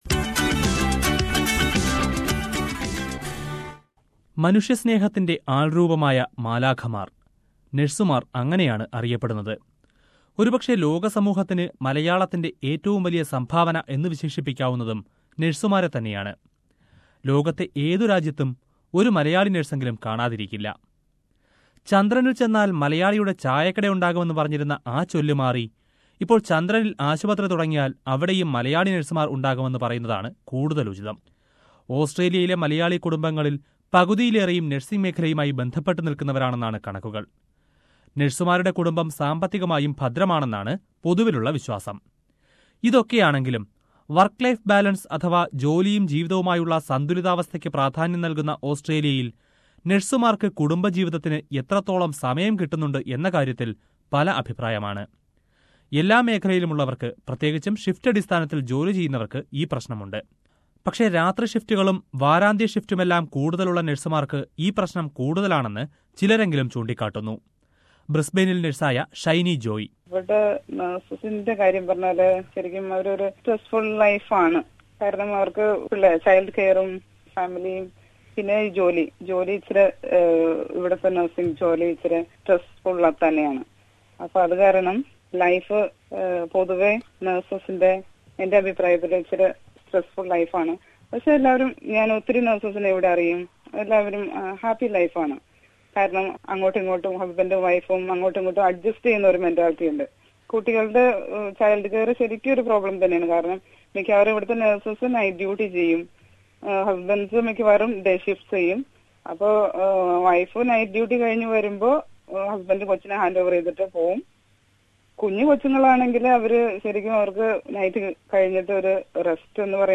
Let us listen to the words of nurses and their family members...